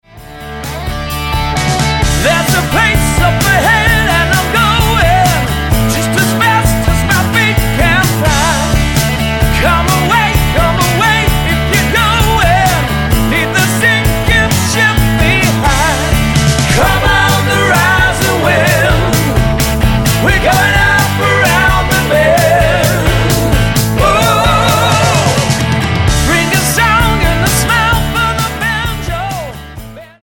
Tonart:D Multifile (kein Sofortdownload.
Die besten Playbacks Instrumentals und Karaoke Versionen .